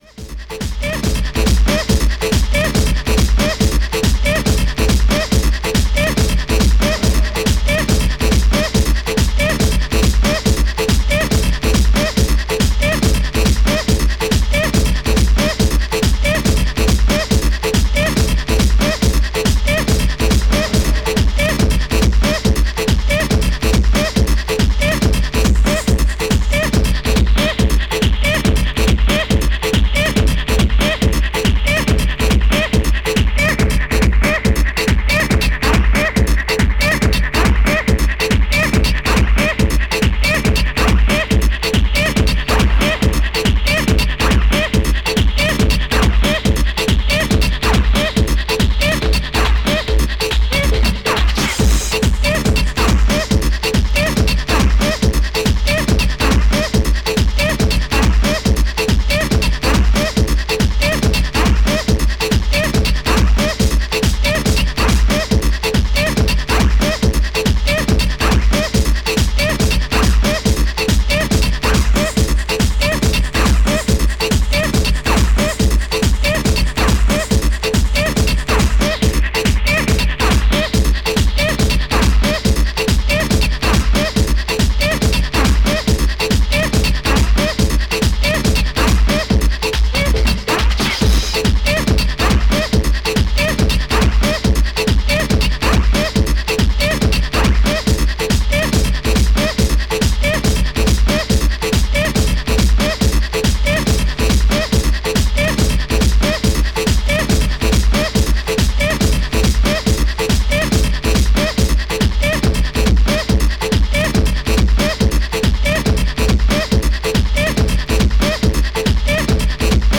STYLE Techno